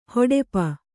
♪ hoḍepa